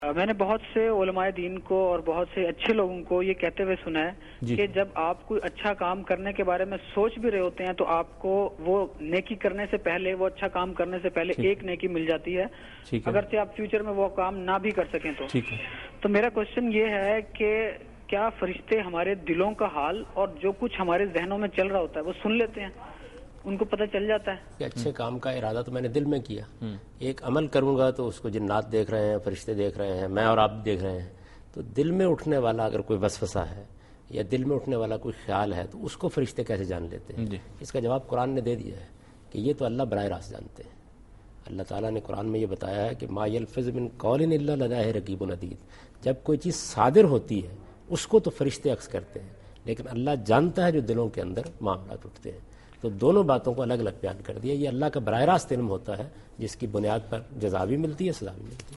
Answer to a Question by Javed Ahmad Ghamidi during a talk show "Deen o Danish" on Duny News TV
دنیا نیوز کے پروگرام دین و دانش میں جاوید احمد غامدی ”فرشتے دلوں کا حال جانتے ہیں“ سے متعلق ایک سوال کا جواب دے رہے ہیں